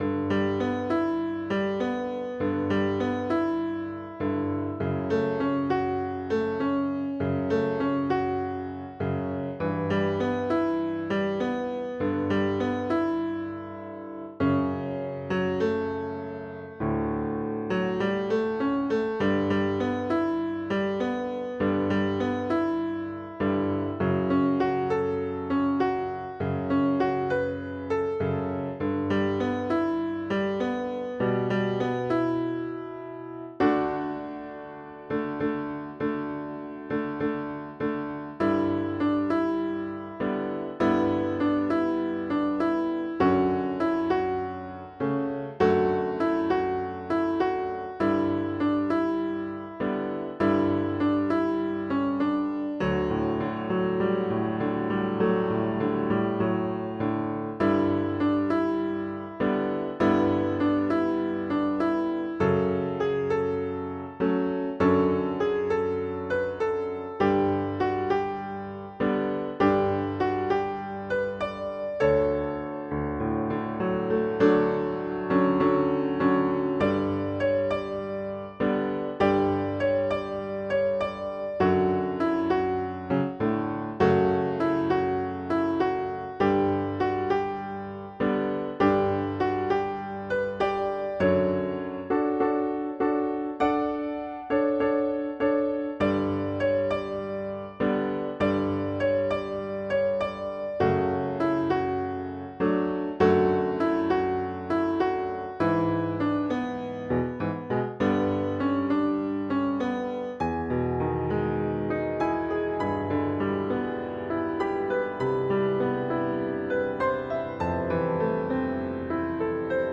Feeling-just-piano-C.wav